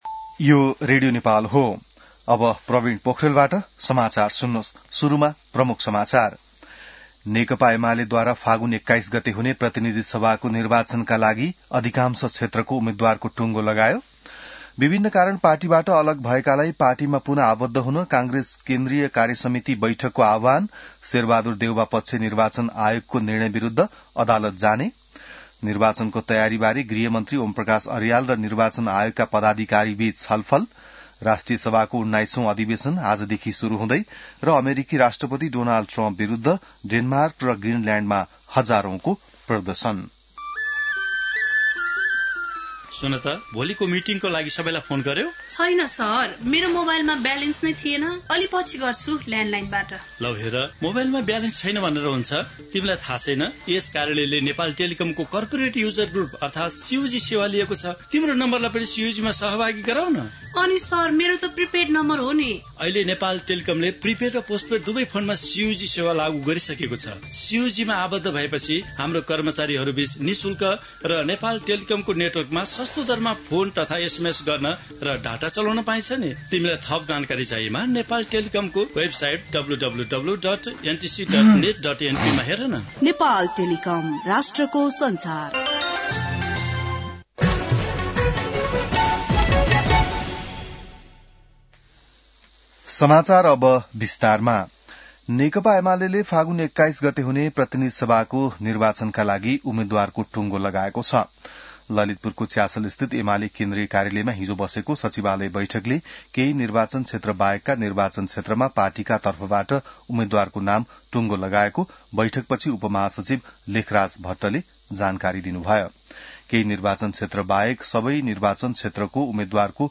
बिहान ७ बजेको नेपाली समाचार : ४ माघ , २०८२